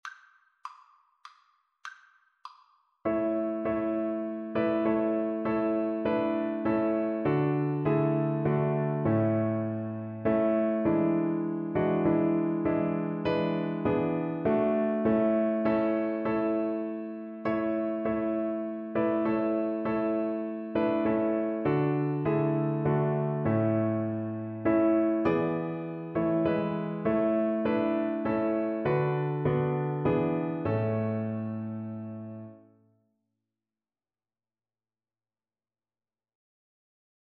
Christmas carol
E5-E6
3/4 (View more 3/4 Music)